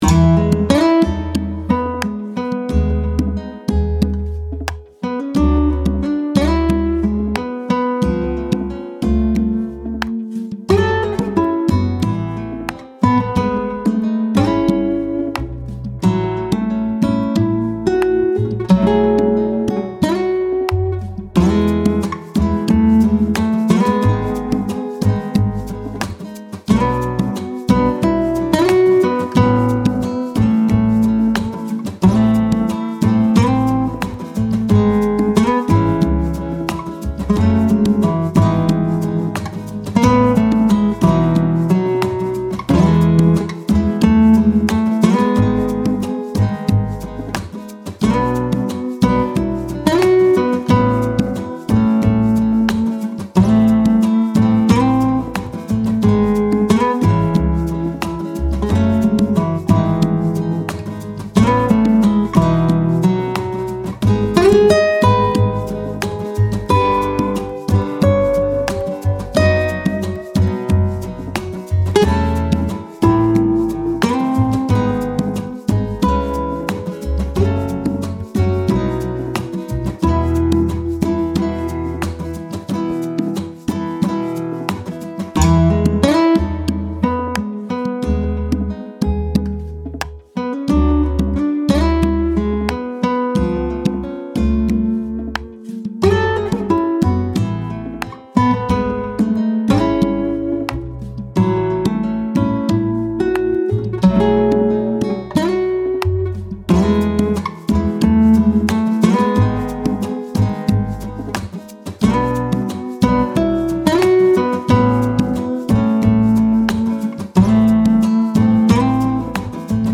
優しいアコースティック調の曲
アコースティック 4:17